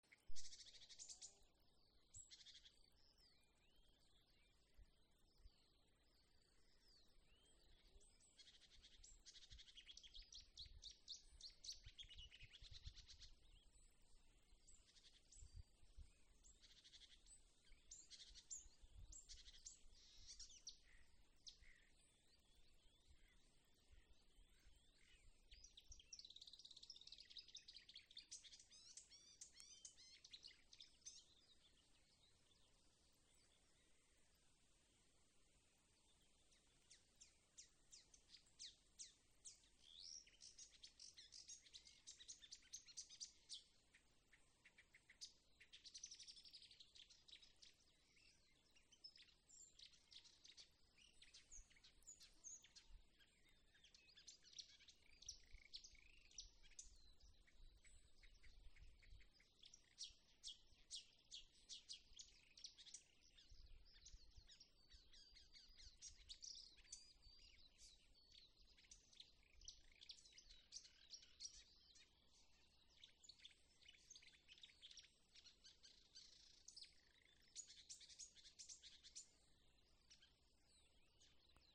Purva ķauķis, Acrocephalus palustris
StatussDzied ligzdošanai piemērotā biotopā (D)
Piezīmes/grāvī, kārklājā, kāds no ķauķīšiem